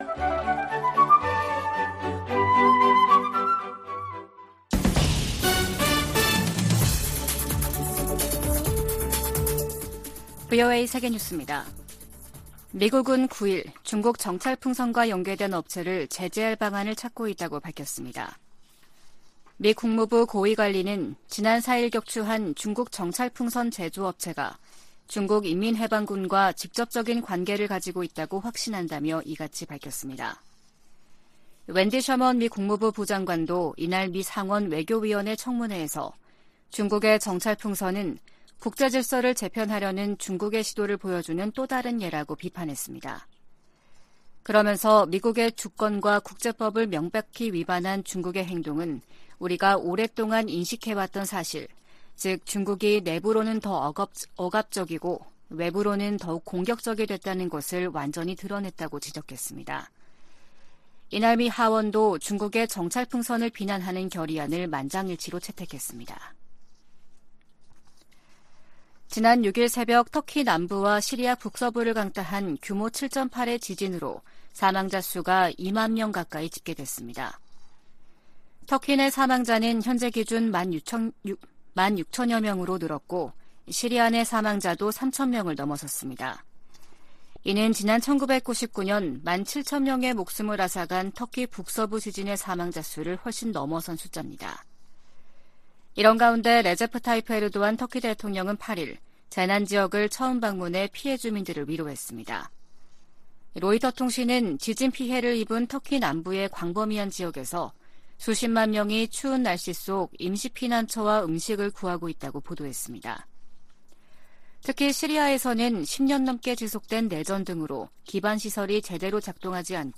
VOA 한국어 아침 뉴스 프로그램 '워싱턴 뉴스 광장' 2023년 2월 10일 방송입니다. 북한 건군절 기념 열병식에서 고체연료 대륙간탄도 미사일, ICBM으로 추정되는 신형 무기가 등장했습니다. 북한의 핵・미사일 관련 조직으로 추정되는 미사일총국이 공개된 데 대해 미국 정부는 북한 미사일 개발을 억지하겠다는 의지를 확인했습니다.